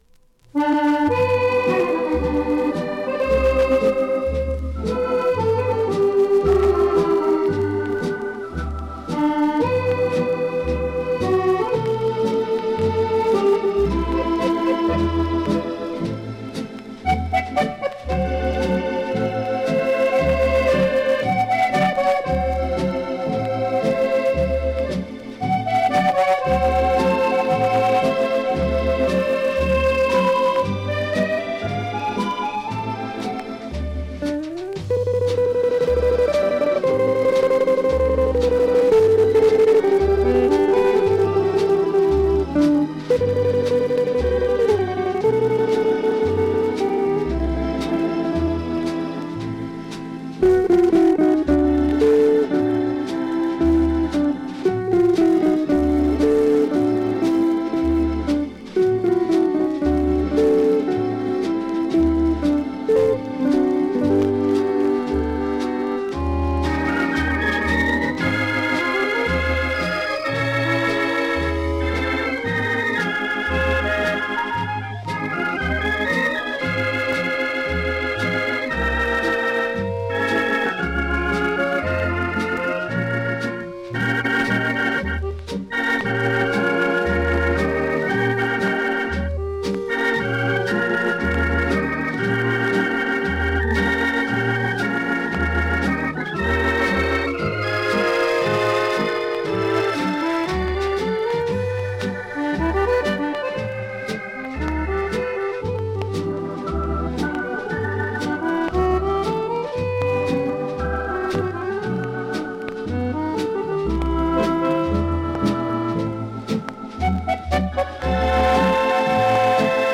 US盤 7"Single 45 RPM現物の試聴（両面すべて録音時間５分１０秒）できます。